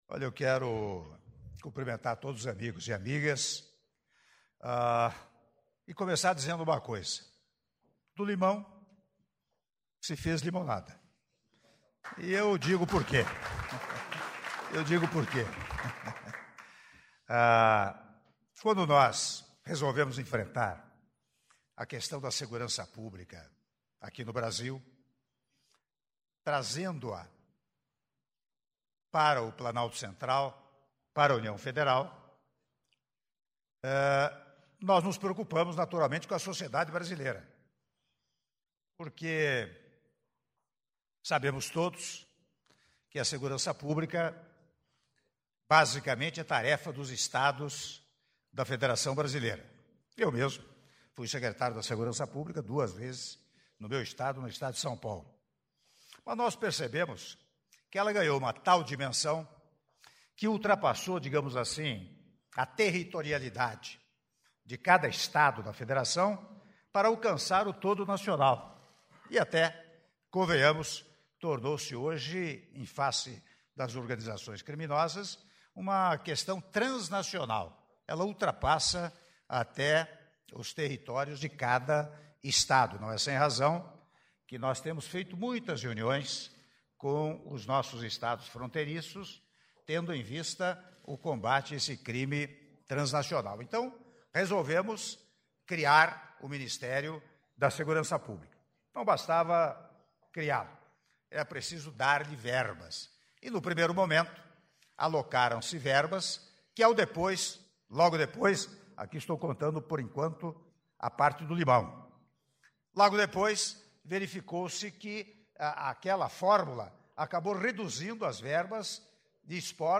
Áudio do discurso do Presidente da República, Michel Temer, durante Cerimônia de Assinatura de Medida Provisória de Verbas para Esporte e Cultura - Palácio do Planalto (07min40s)